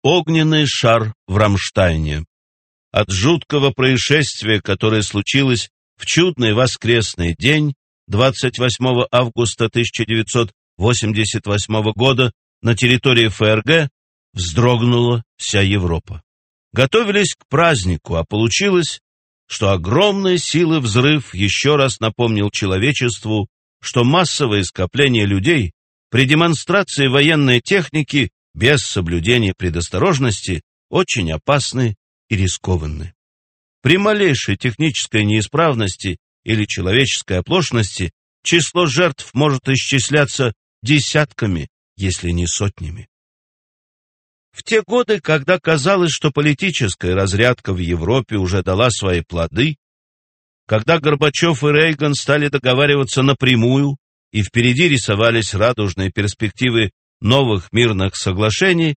Аудиокнига Катастрофы-1 (Гибнущий лес) | Библиотека аудиокниг